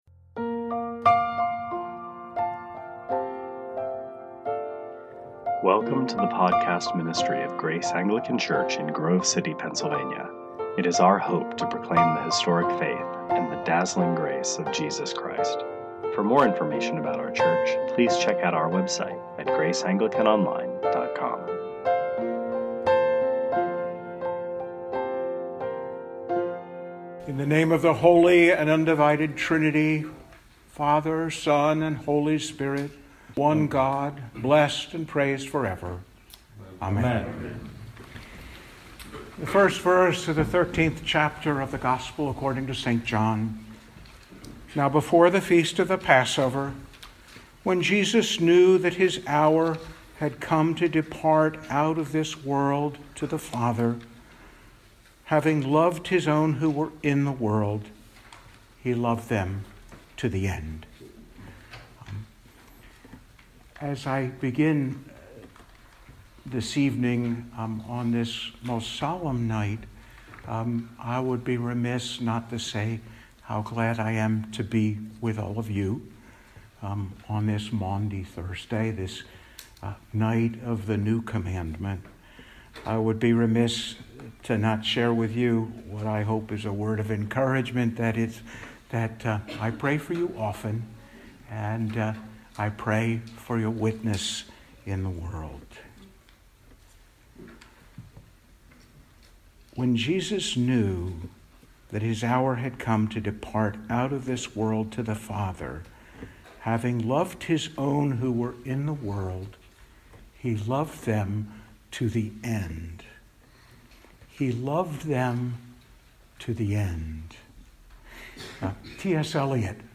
The Most Rev Robert Wm Duncan, Archbishop Emeritus (ACNA)
2018 Sermons